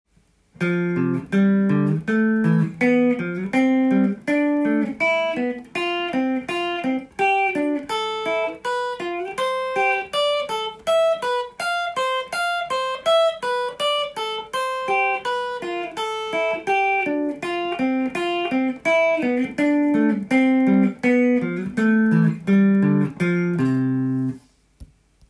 度数ごとのCメジャースケール練習】
４度進行
ドからファ　レからソ　などが４度の並びになります。